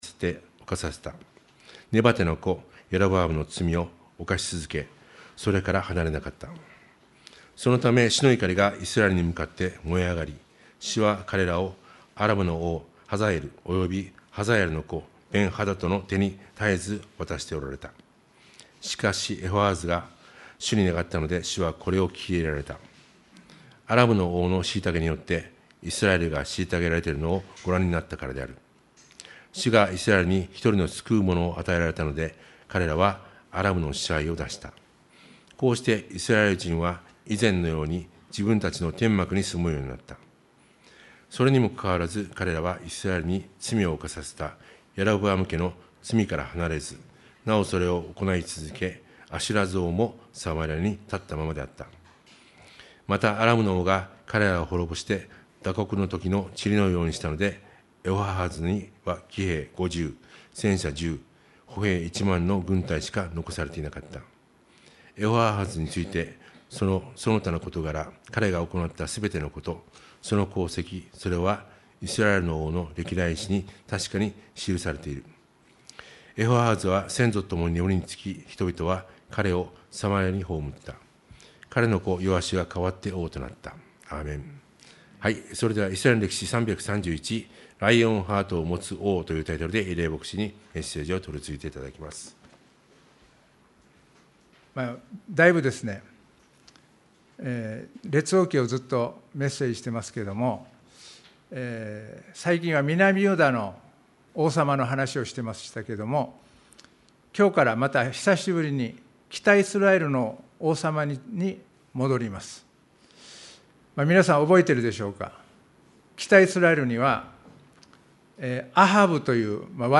沖縄県浦添市にある沖縄バプテスト連盟所属ルア教会です。
2025年9月14日礼拝メッセージ